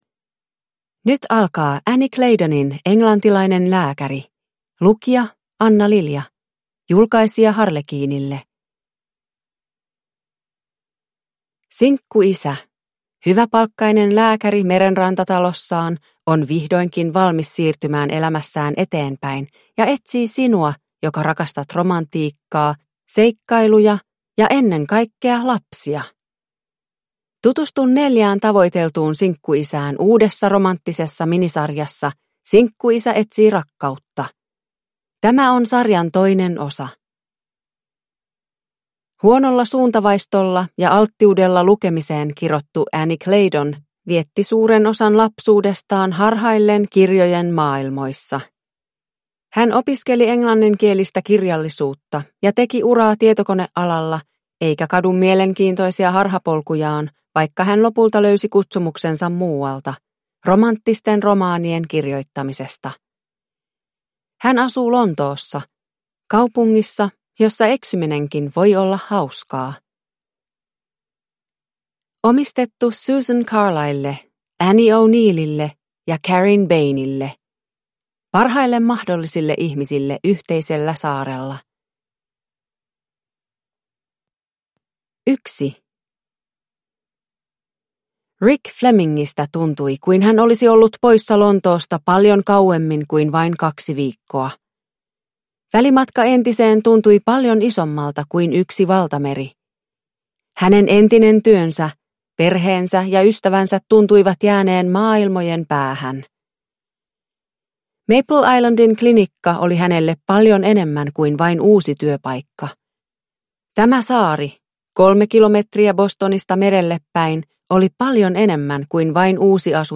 Englantilainen lääkäri (ljudbok) av Annie Claydon